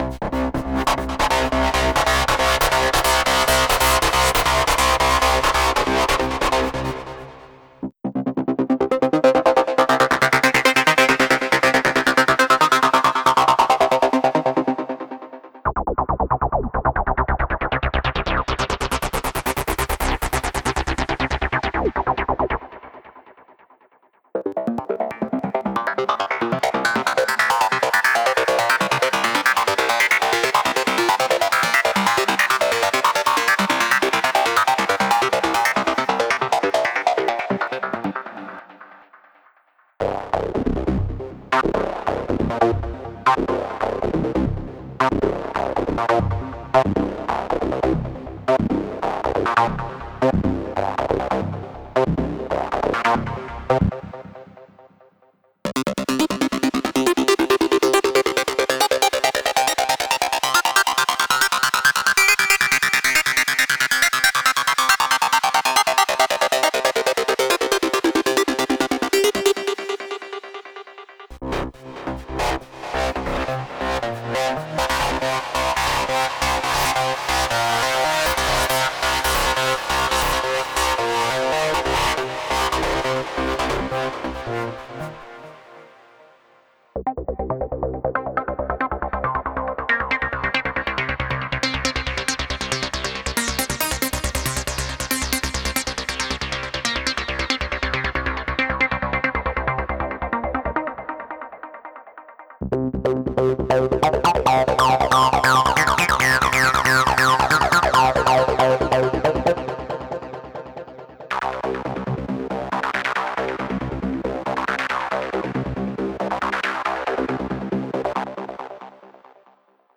Trance
a brand new Spire preset dedicated to modern trance sounds.
The pack also includes 20 ACID MIDI patterns you can hear it in audiodemo.